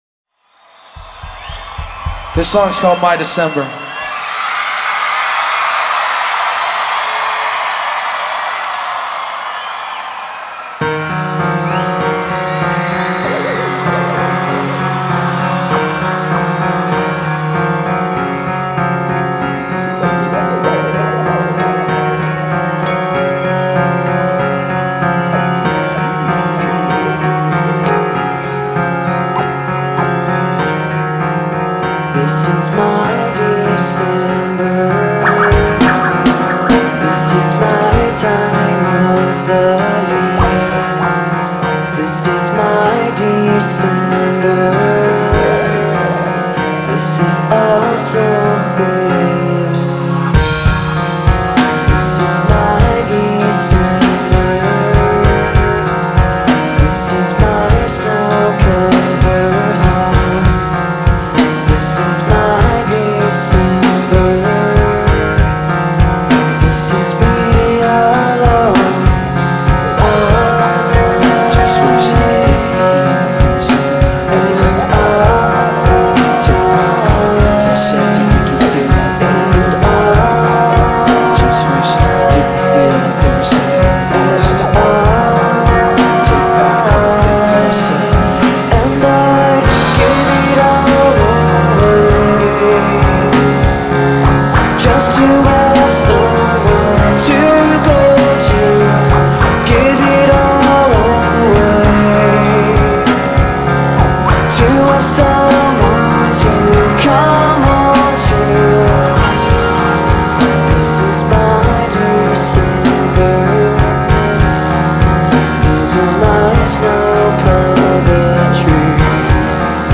Live Projekt Revolution 2002